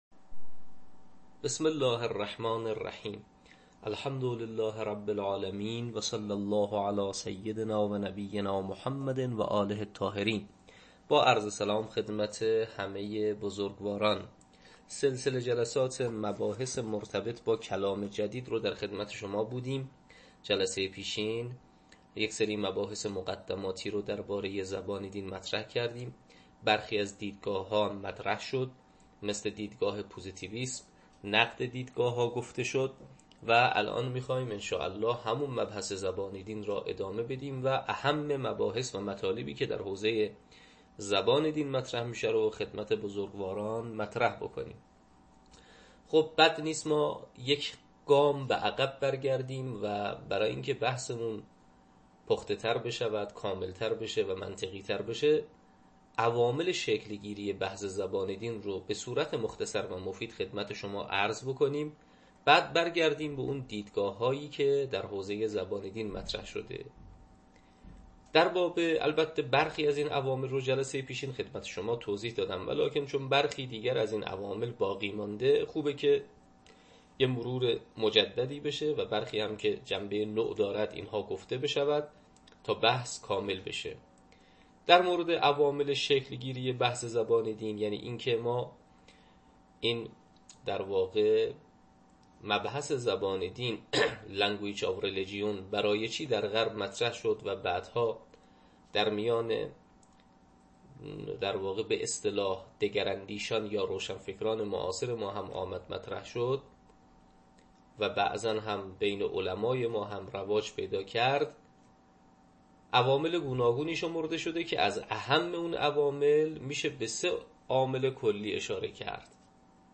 تدریس کلام جدید